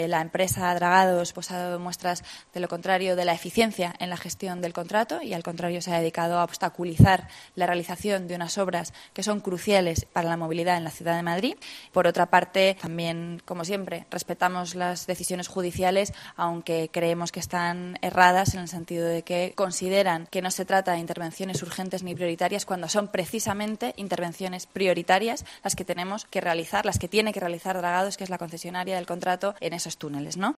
Así lo ha señalado este jueves la portavoz del Gobierno municipal, Rita Maestre, en la rueda de prensa posterior a la Junta.